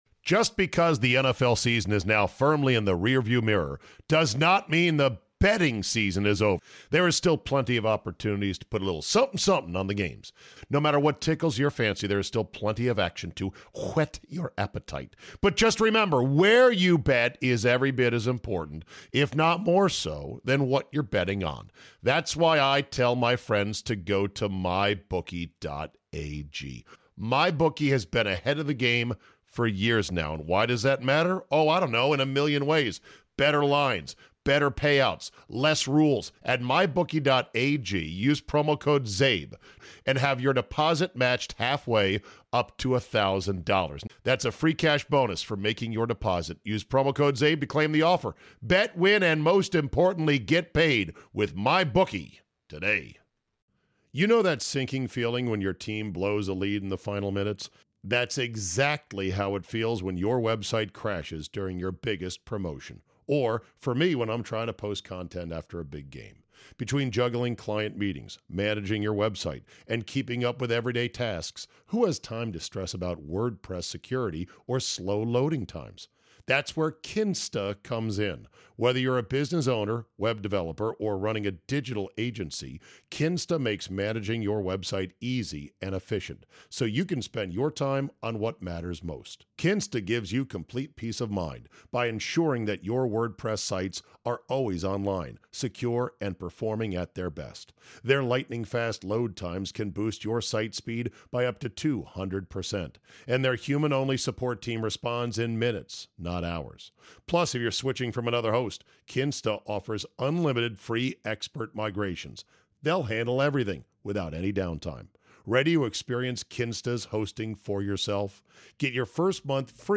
does a solo run this morning, live from the strip